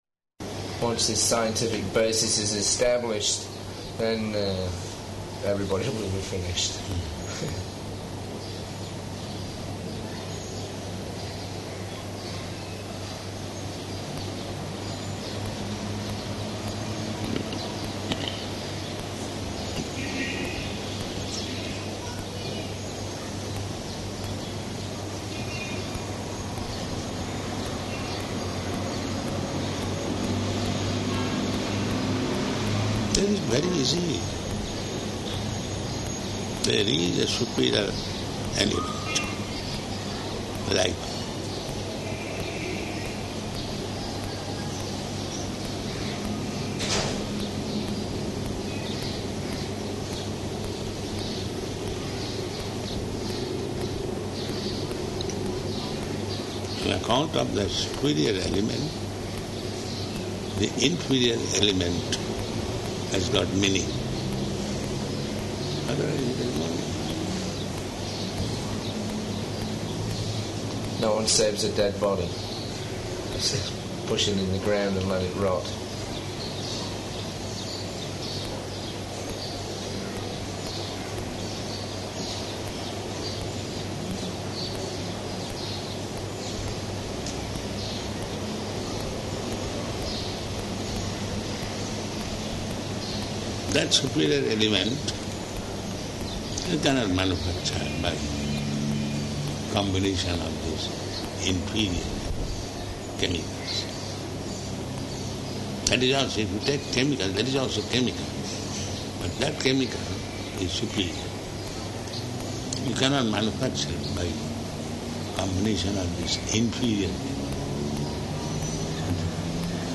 Room Conversation
-- Type: Conversation Dated: March 27th 1977 Location: Bombay Audio file